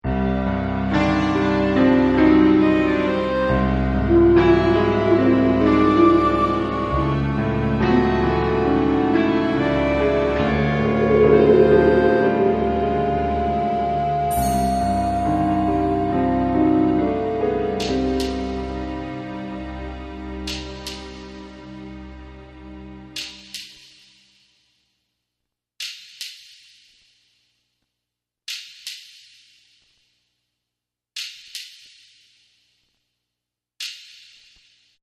Thriller